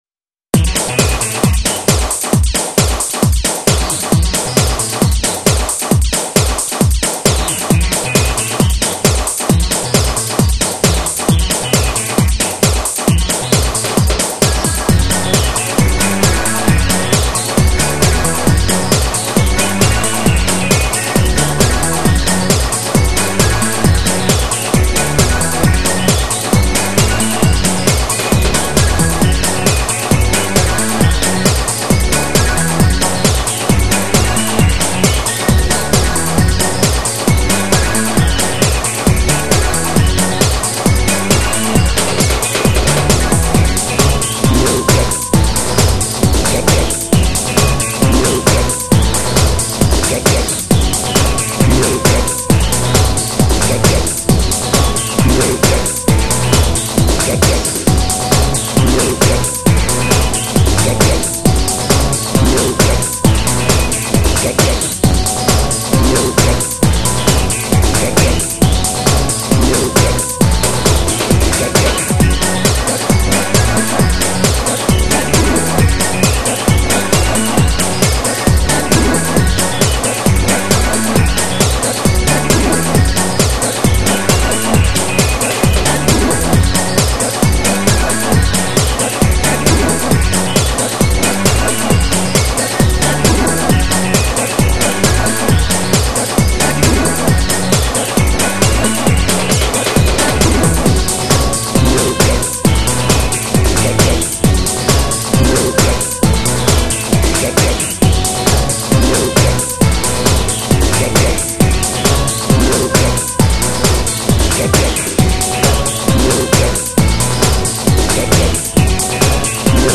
幅広く 『ハウス』 の攻略を試みたオリジナル曲集。